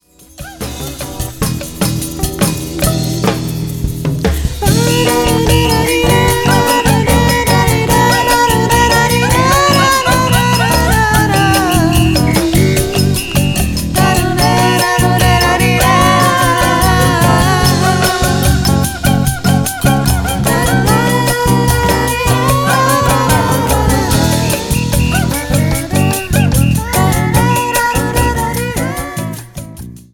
Violão/Voz
Baixo/Apito